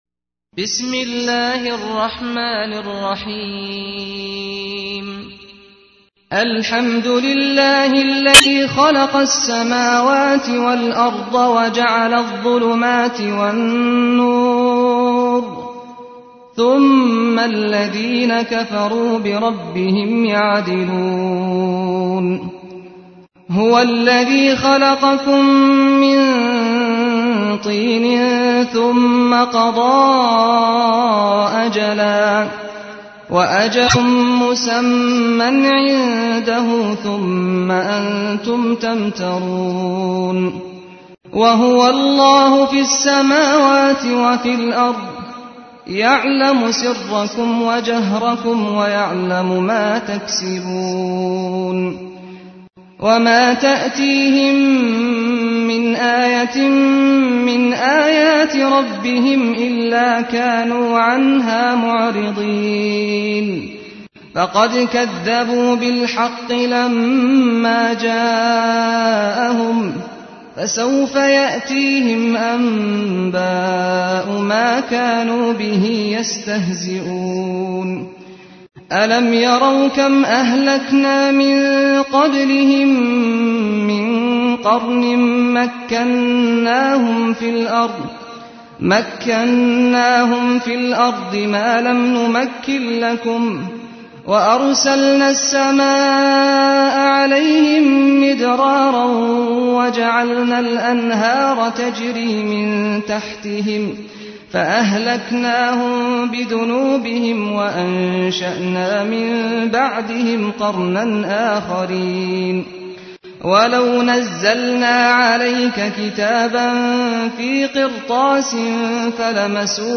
تحميل : 6. سورة الأنعام / القارئ سعد الغامدي / القرآن الكريم / موقع يا حسين